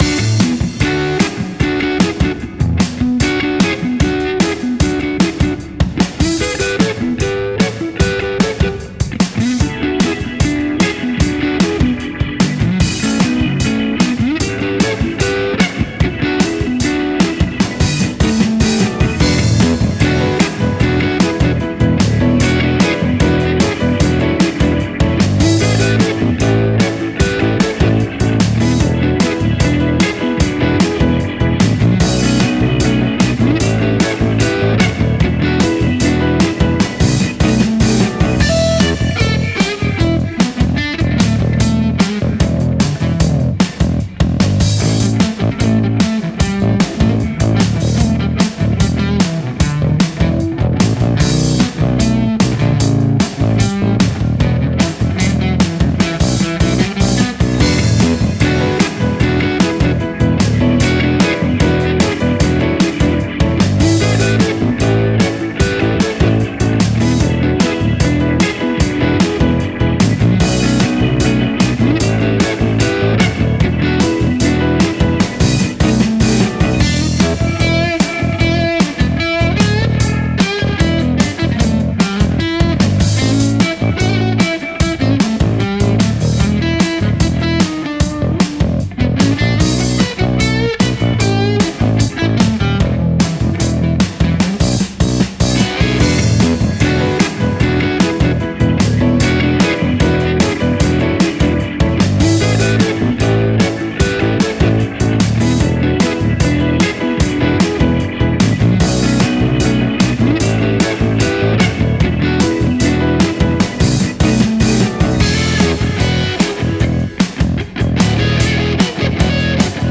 60’s Blues Clip- Short Affirmation